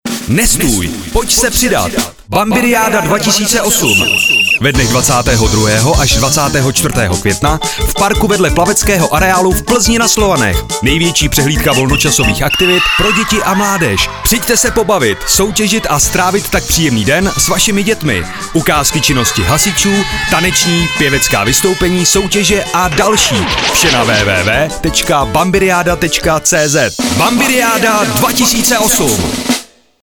Spot z rádia Blaník